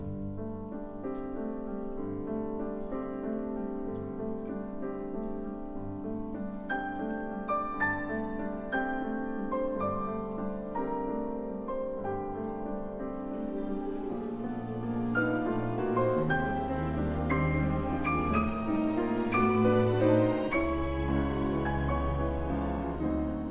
Orchestration : 2 flûtes (la 2e également piccolo), 2 hautbois, 2 clarinettes, 2 bassons, 2 trompettes, 2 cors, trombone, trombone basse, tuba, timbales, grosse caisse, caisse claire, cordes.
Version choisie pour les extraits : prestation de Anna Vinnitskaya et l'Orchestre National de Belgique sous la direction de Gilbert Varga lors de la finale du Concours Reine Elisabeth et avec lequel elle remporta le 1er Prix en 2007.
Le premier mouvement s'ouvre aux cordes “con sordino” en pizzicato et aux clarinettes sur lesquelles entre le piano, piano, sur un balancement de mesure ternaire (12/8), “narrante” tel un conte issu de vieilles légendes, une mélodie au caractère slave.